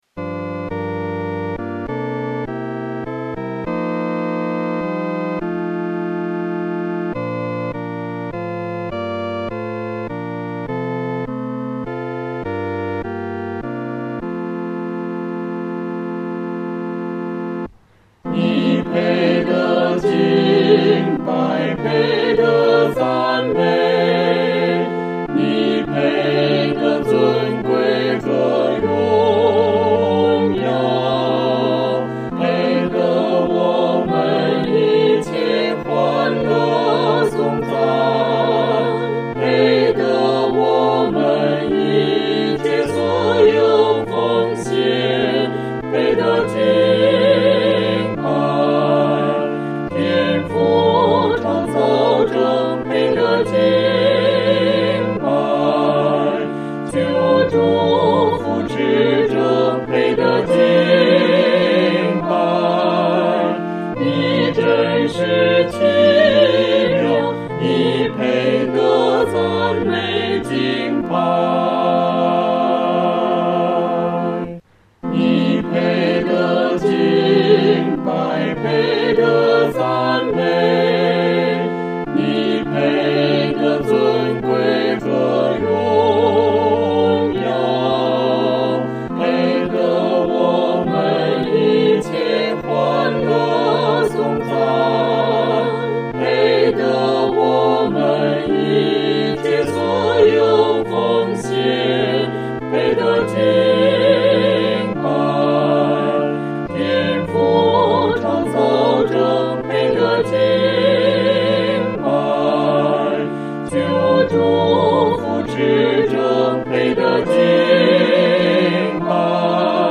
合唱 四声